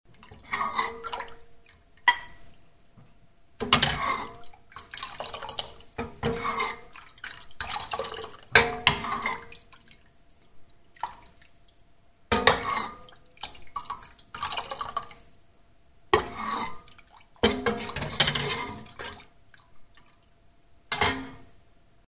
Звук ложки в супе мешаем и кушаем